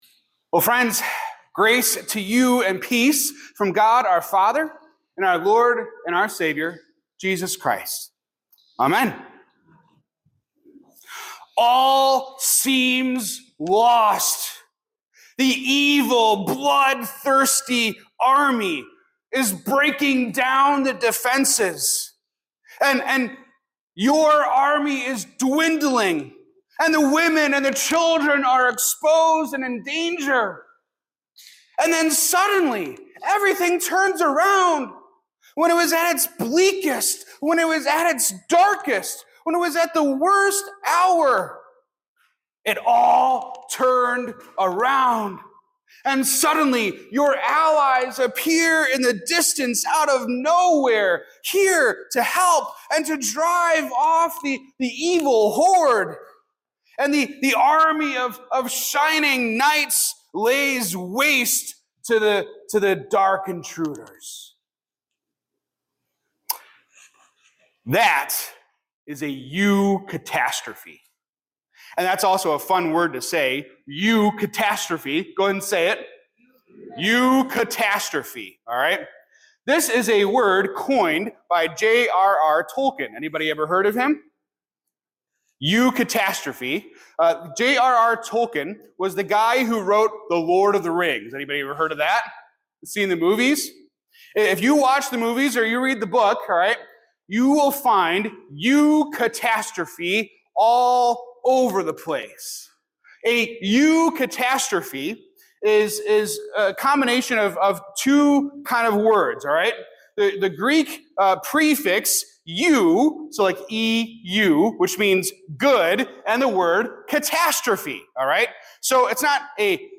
This sermon unpacks the concept of "eucatastrophe," a sudden, good catastrophe, as a lens to understand God's redemptive work.